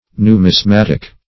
Numismatic \Nu`mis*mat"ic\, Numismatical \Nu`mis*mat"ic*al\, a.